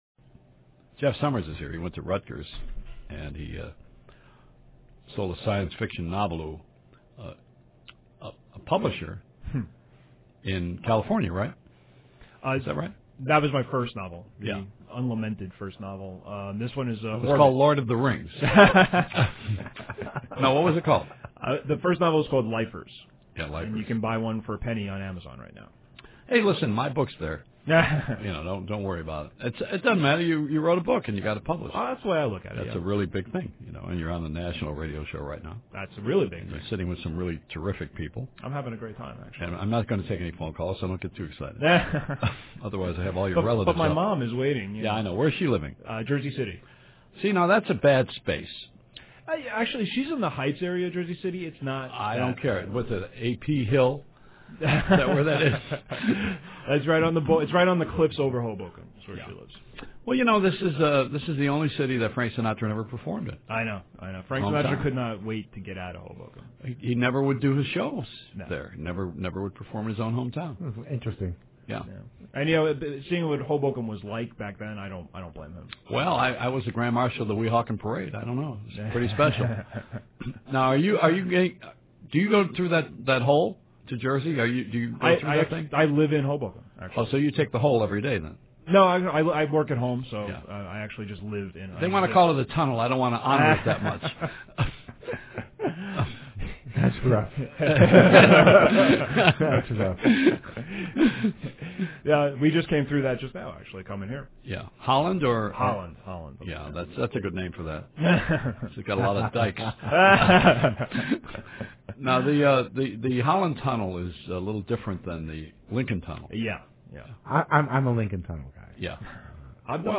So, I was on the radio last night, on the Joey Reynolds Show, and it was a blast.
If nothing else, my name and the title of my book were mentioned a lot, and my pants didn’t fall down while the dying Pac Man sound played softly in the background, so it was a triumph.
Anyway, here are some lo-fi MP3s of the show, broken up into 3 delicious parts.